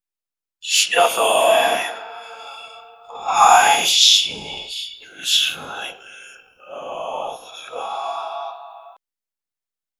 Priest gather funeral burial reads bible for mafuyu asahina's funeral who she ia dies in a accident cae 0:10 The priest is wearing wooden sandals and walking very slowly inside the temple. 0:10 hasserfüllte Beschwörungsformel eines Ägyptischen Tempelpriesters, geflüstert 0:10
hasserfllte-beschwrungsfo-c4r6lkel.wav